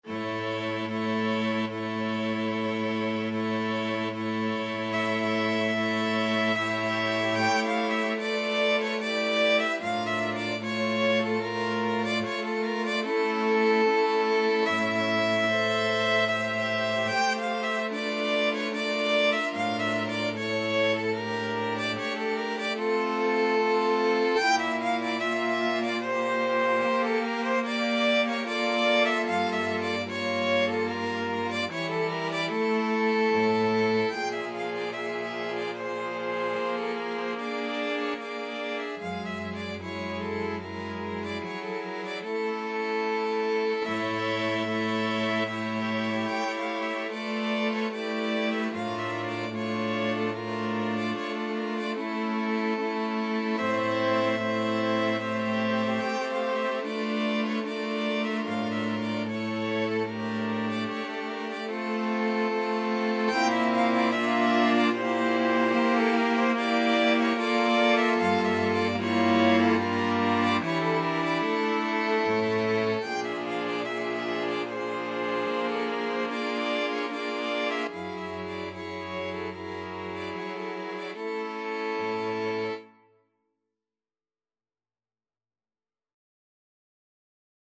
Classical Bartók, Béla Romanian Folk Dance No. 4 Buciumeana String Quartet version
Violin 1Violin 2ViolaCello
F major (Sounding Pitch) (View more F major Music for String Quartet )
Moderato ( = 74)
3/4 (View more 3/4 Music)
Classical (View more Classical String Quartet Music)